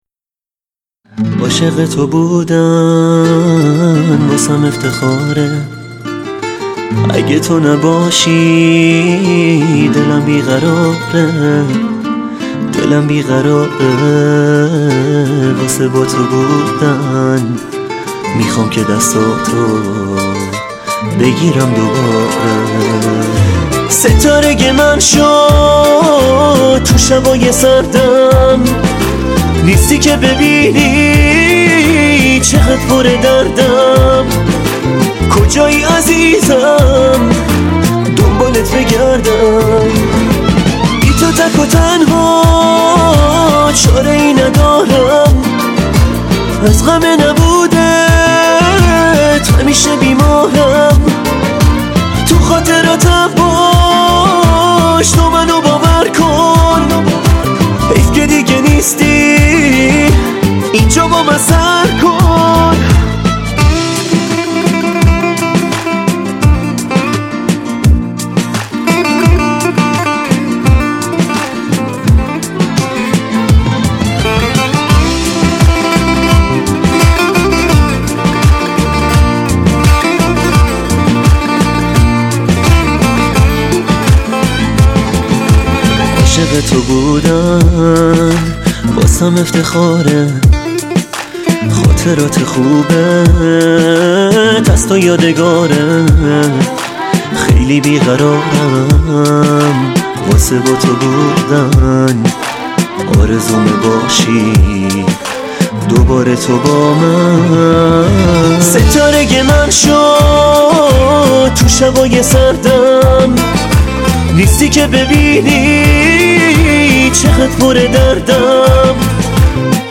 دسته بندی : دانلود آهنگ غمگین تاریخ : جمعه 25 ژانویه 2019